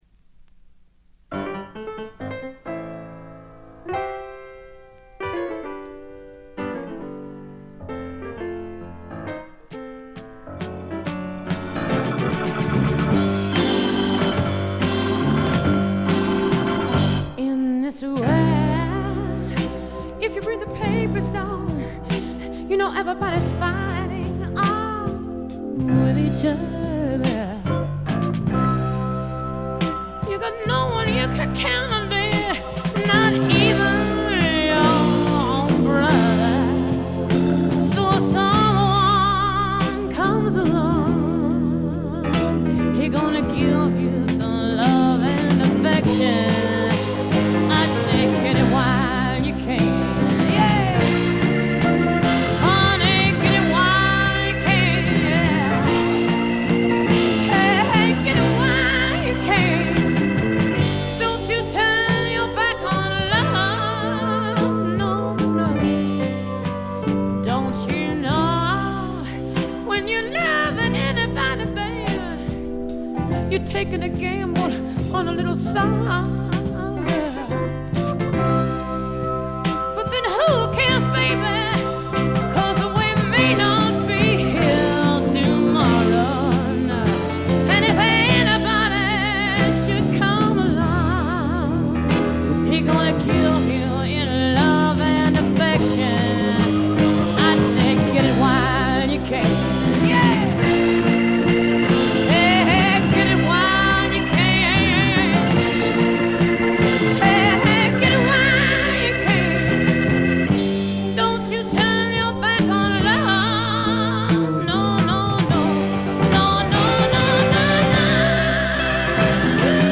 これ以外は細かなバックグラウンドノイズ、チリプチ少々。
試聴曲は現品からの取り込み音源です。